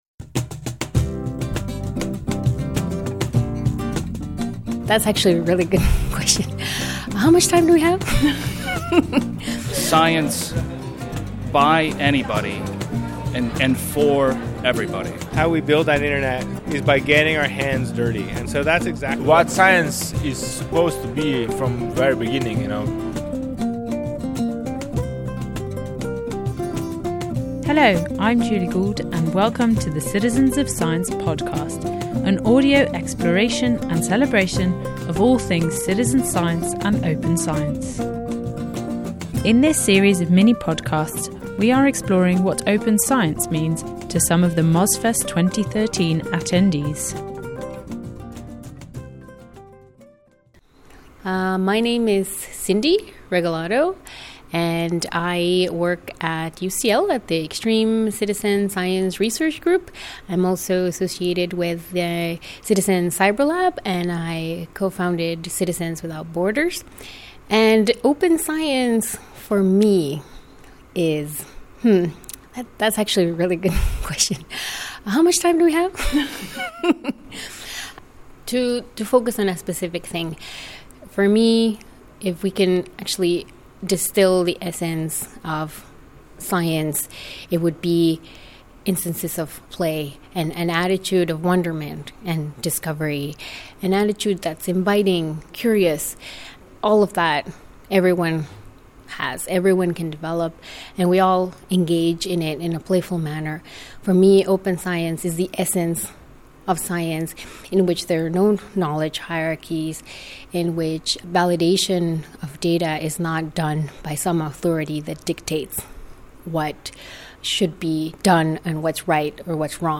An audio exploration of what Open Science means to some of the attendees of Mozfest 2013.